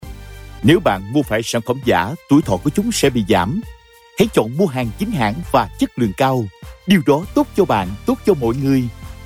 Vietnamese voice over